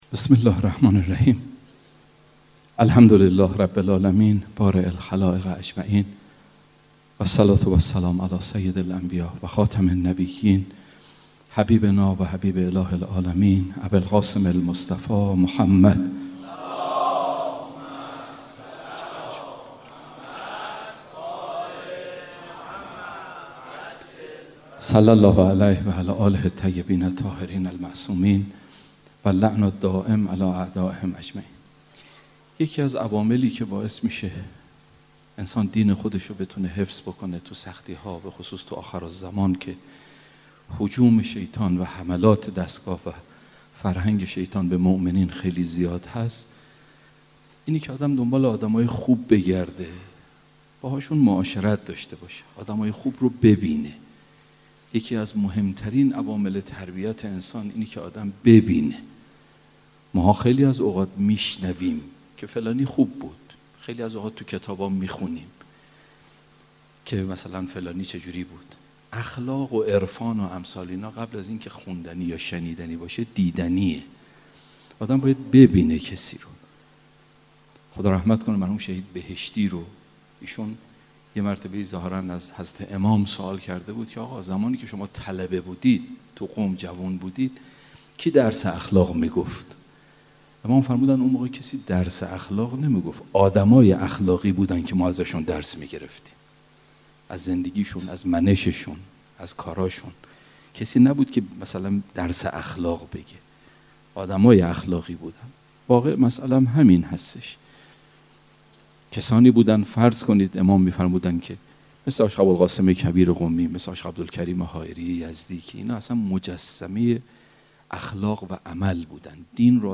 شب هشتم فاطمیه 96 - مسجد الهادی علیه السلام